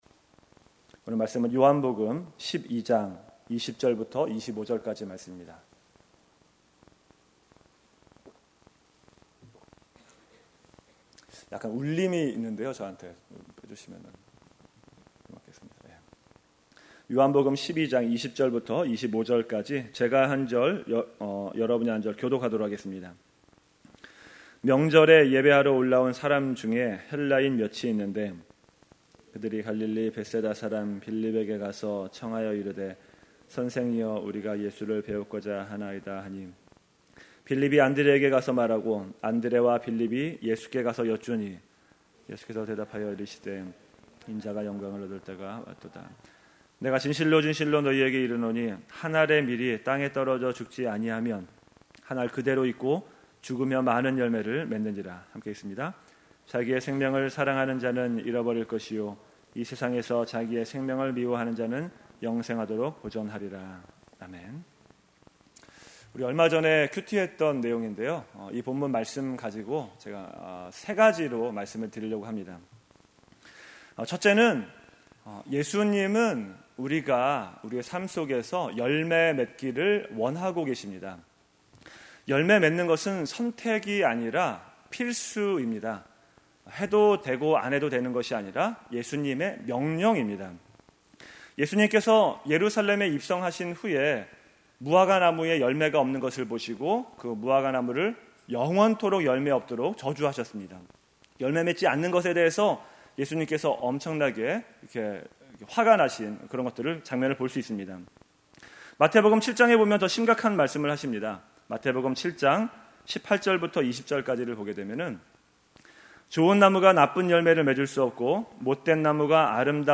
금요설교